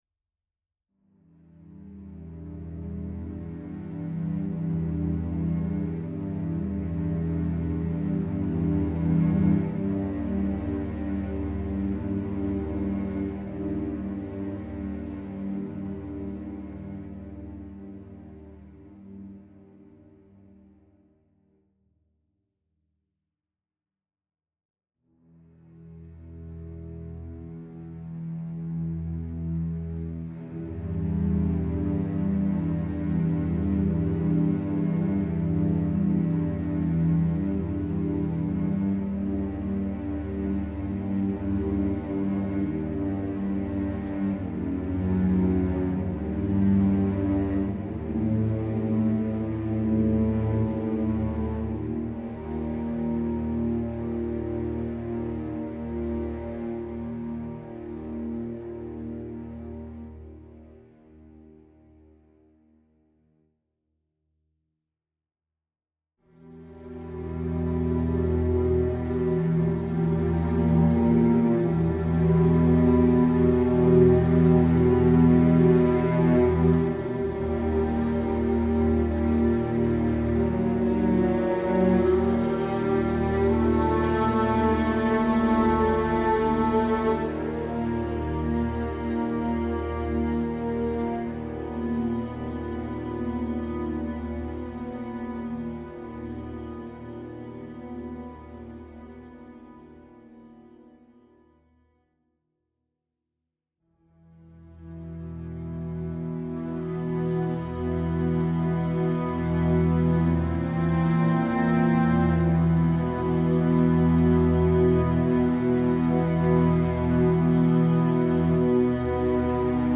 strings only